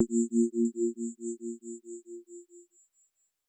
tone4.L.wav